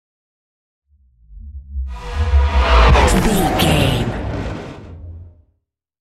Sci fi super speed vehicle whoosh
Sound Effects
futuristic
whoosh
vehicle